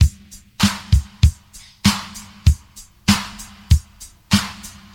• 97 Bpm Fresh Drum Loop Sample D Key.wav
Free breakbeat sample - kick tuned to the D note. Loudest frequency: 1444Hz
97-bpm-fresh-drum-loop-sample-d-key-6cJ.wav